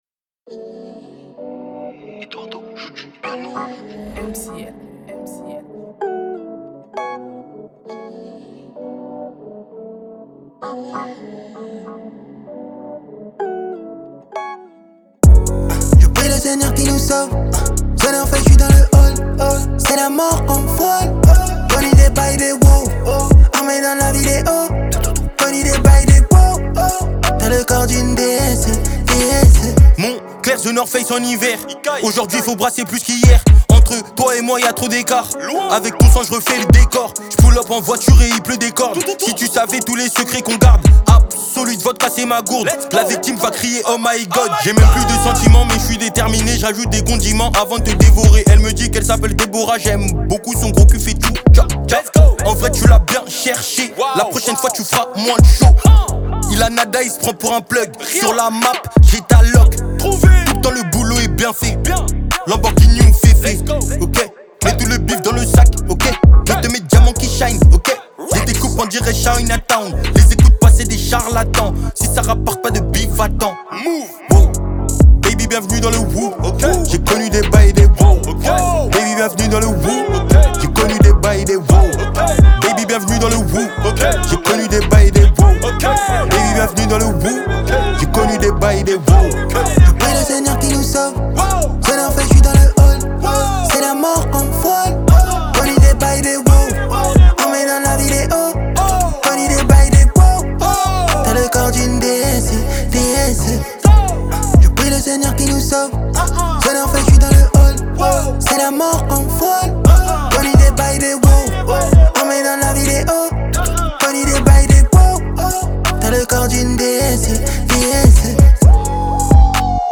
french rap, pop urbaine Télécharger